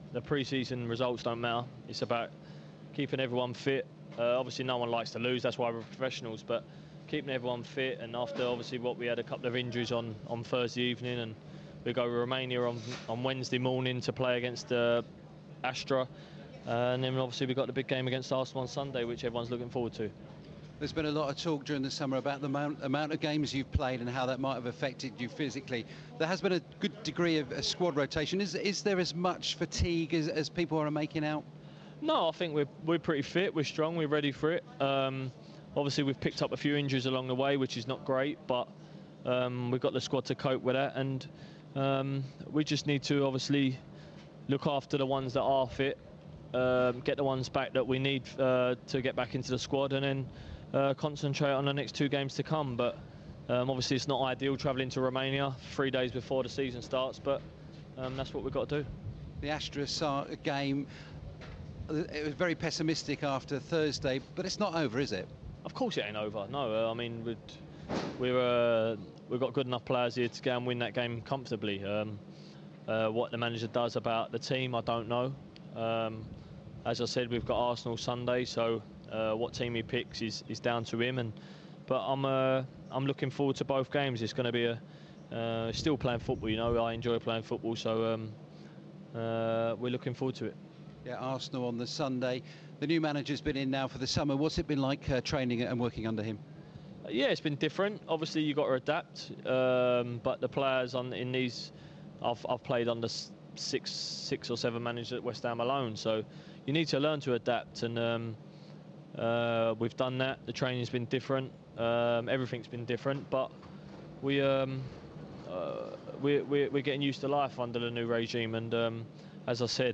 Mark Noble post-match interview after West Ham's 2-1 loss to Werder Bremen